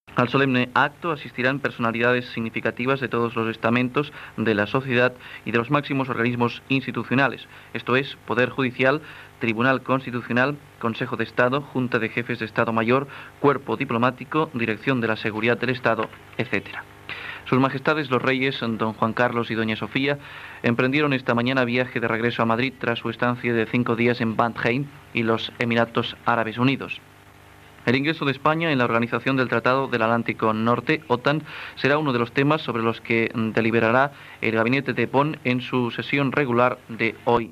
Butlletí informatiu: viatge dels reis d'Espanya als Emirats Àrabs Units, ingrés d'Espanya a l'OTAN
Informatiu